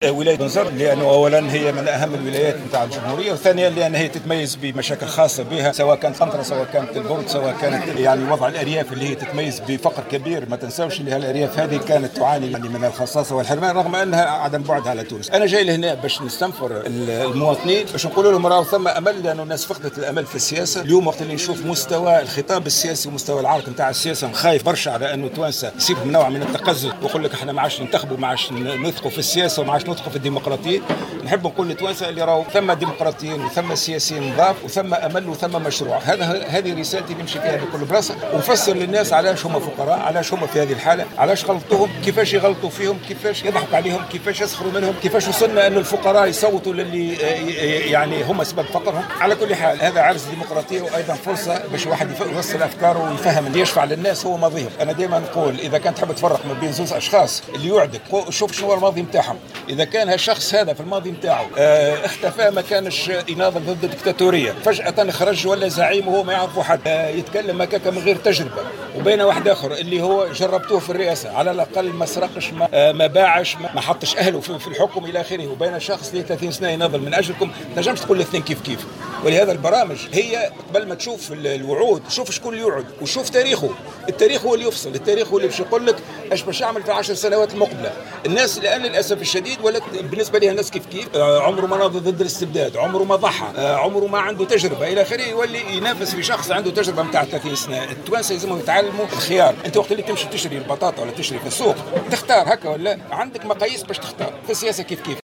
وفي سياق متصل تحدث المرزوقي في تصريح لمراسل الجوهرة أف ام، عن أبرز مشاكل جهة بنزرت التي يجب حلها وعلى رأسها الفقر المتفشي في أريافها، ومعضلة الجسر.